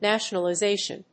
音節na・tion・a・li・za・tion 発音記号・読み方
/n`æʃ(ə)nəlɪzéɪʃən(米国英語), ˌnæʃʌnʌlʌˈzeɪʃʌn(英国英語)/